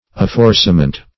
Afforciament \Af*for"ci*a*ment\, n.